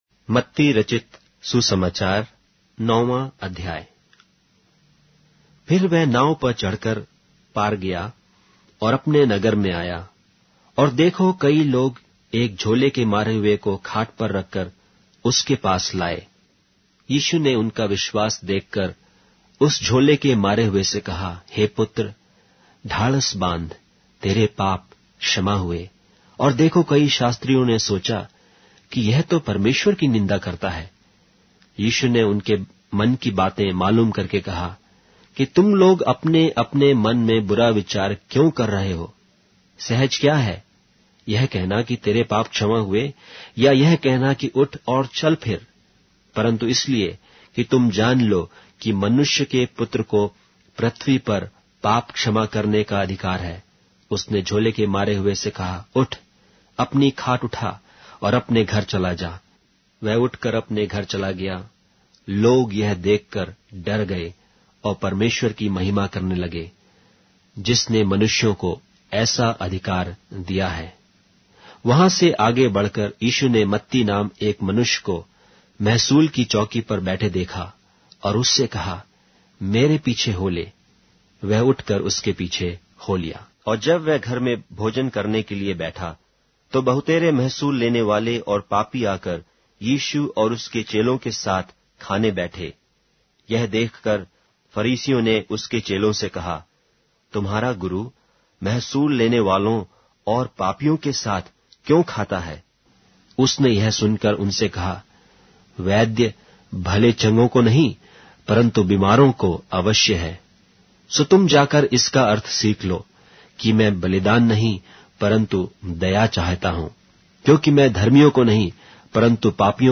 Hindi Audio Bible - Matthew 10 in Hov bible version